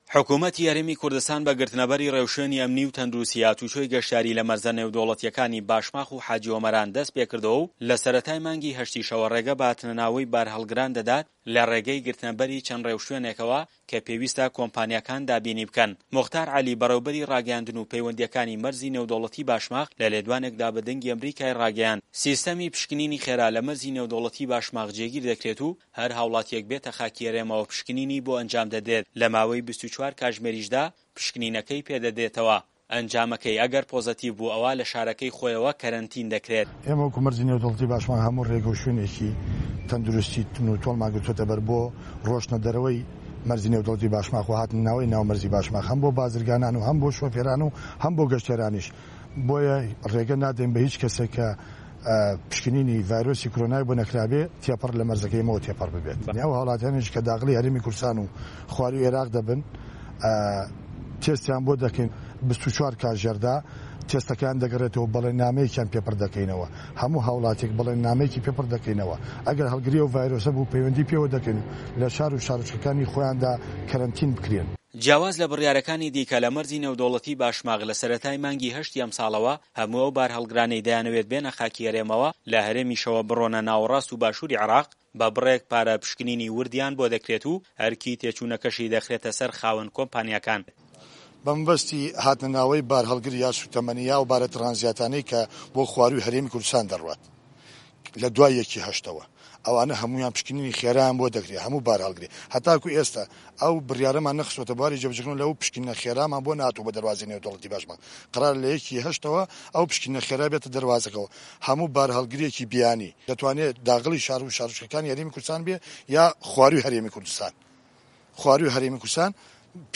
زانیاری زیاتر له‌ ده‌قی ڕاپورته‌که‌دایه‌